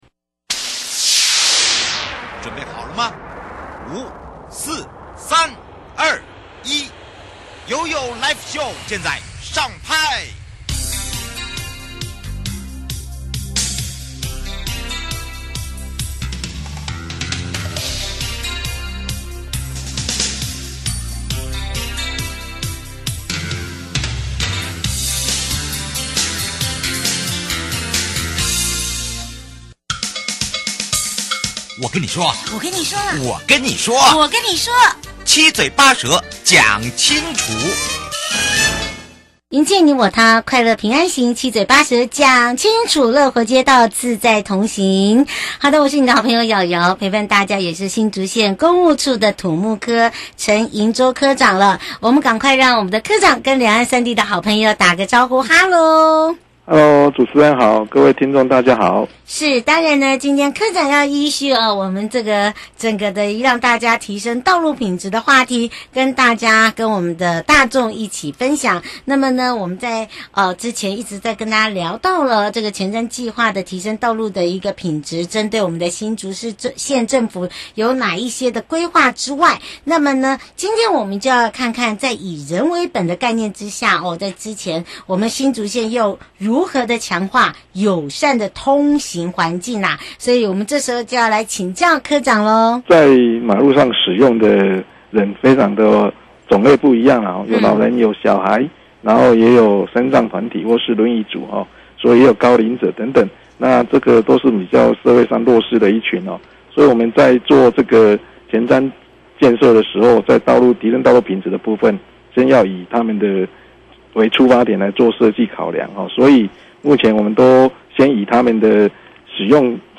受訪者： 在以人為本的概念下 新竹縣政府如何強化友善的通行環境?對提升道路品質計畫的推動感想?還有什麼政策配套